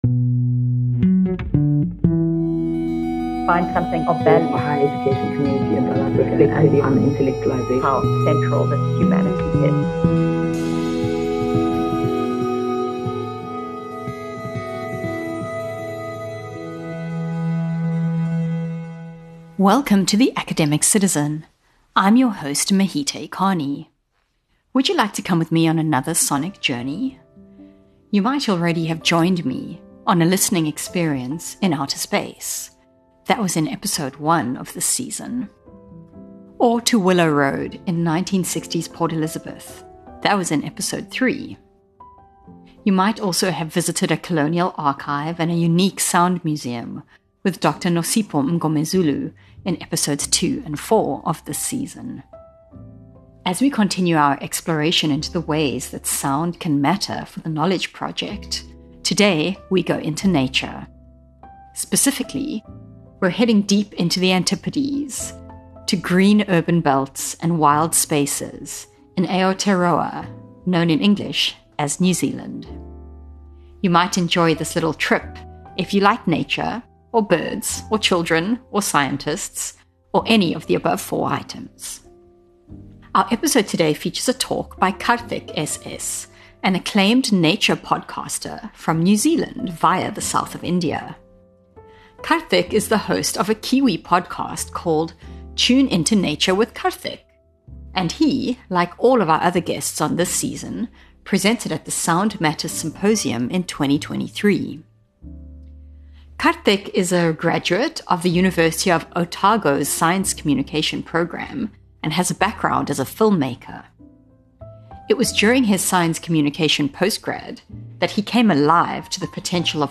Nature Soundscapes